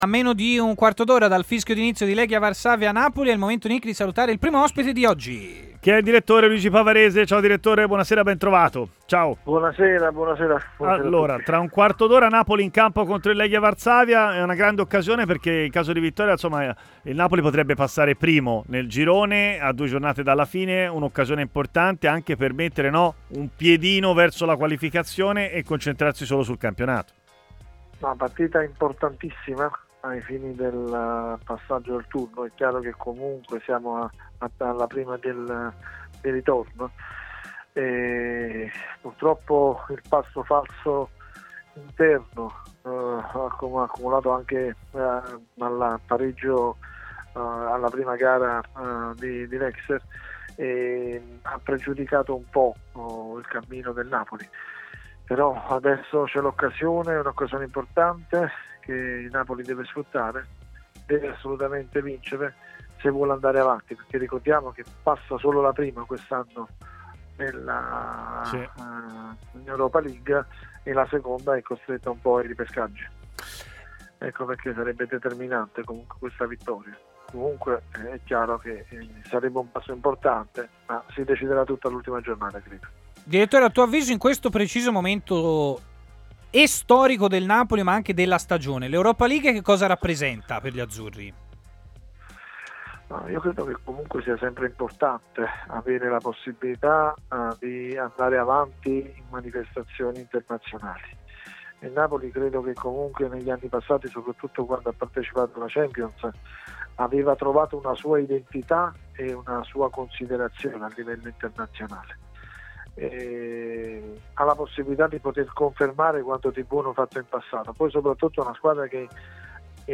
intervenuto in diretta a Stadio Aperto, trasmissione di TMW Radio